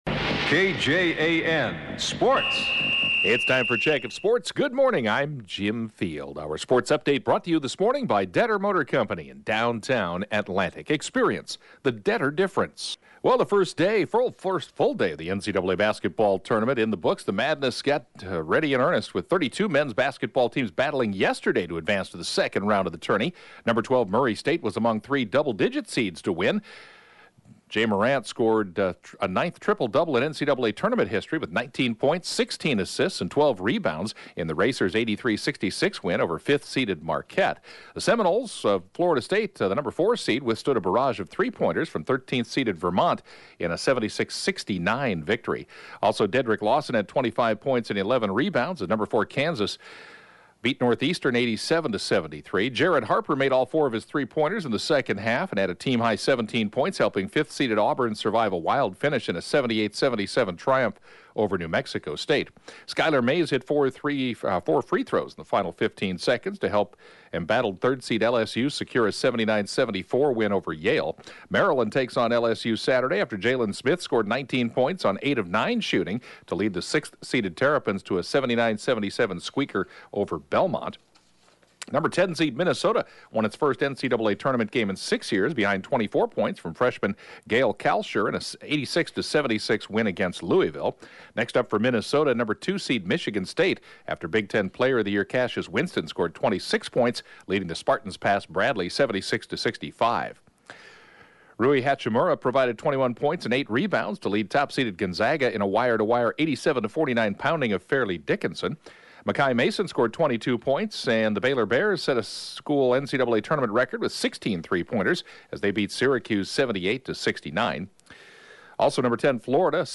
The 7:20-a.m. Sportscast